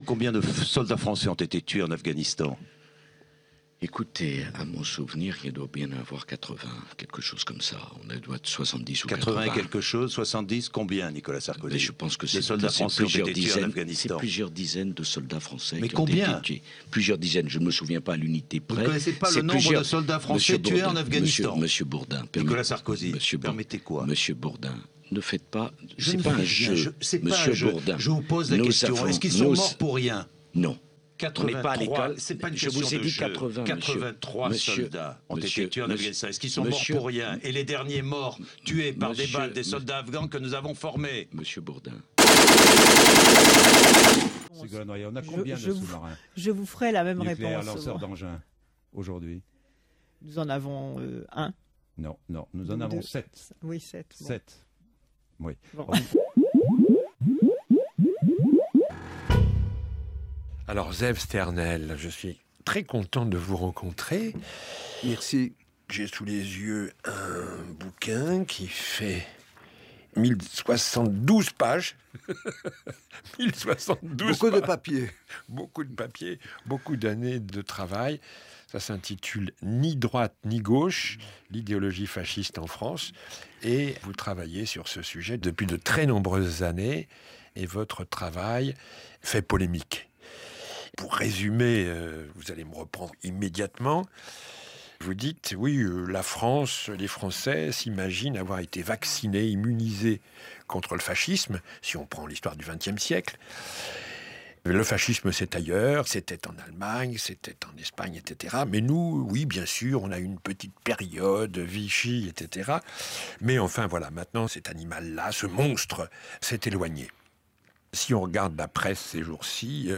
2ème écoute, extrait d’interview
- Jean-Jacques Bourdin interview N. Sarkozy puis Ségolène Royal. à réécouter ici
- Daniel Mermet interview Zeev Sternhell.
- Son brut de micro trottoire à St Herblain